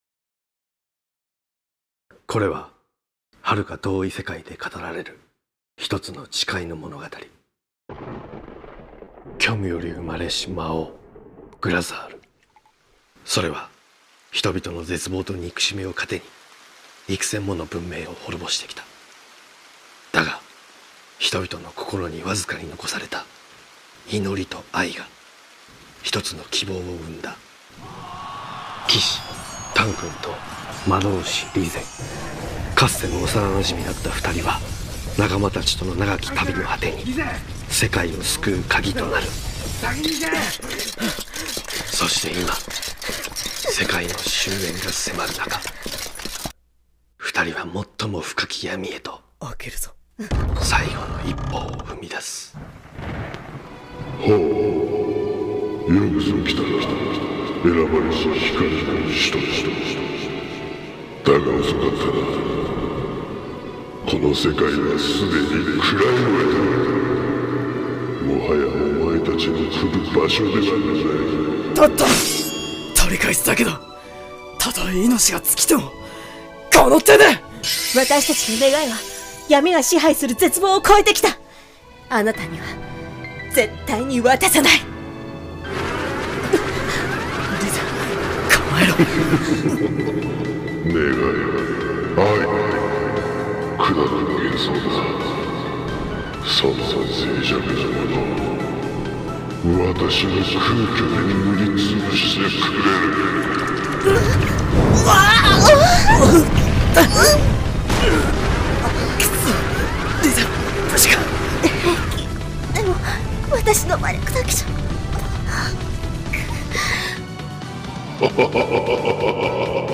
【2人声劇】詠唱 〜想い、届く時〜